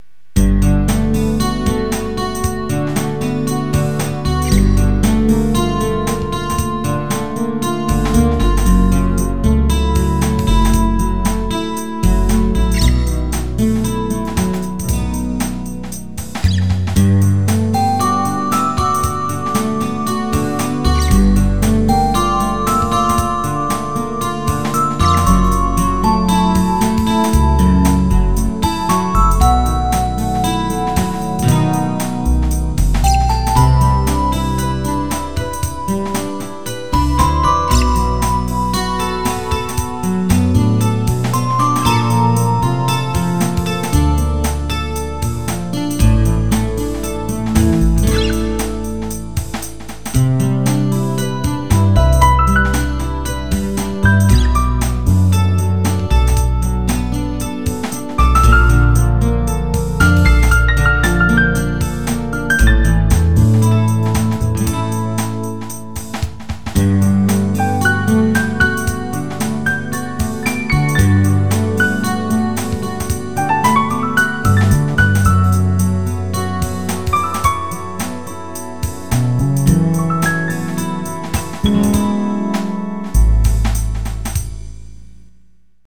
こっちの方がえろげっぽいよね。